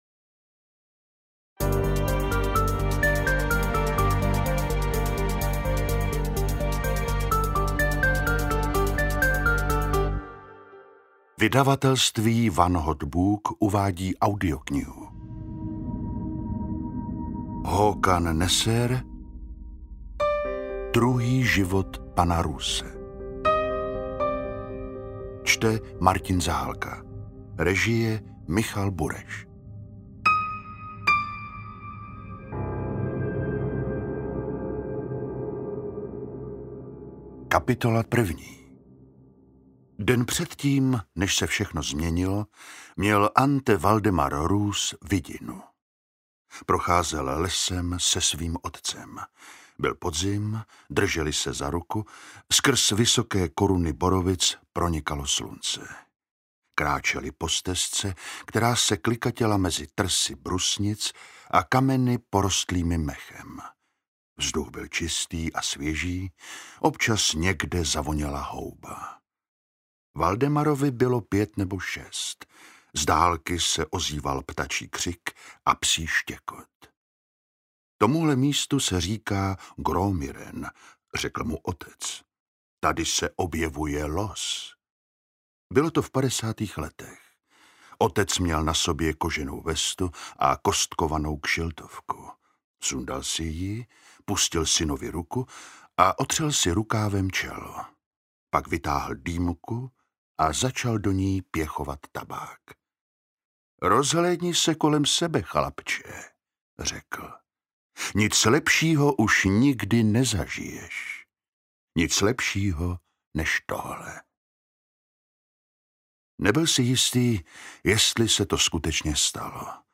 Interpret:  Martin Zahálka
AudioKniha ke stažení, 53 x mp3, délka 16 hod. 8 min., velikost 889,8 MB, česky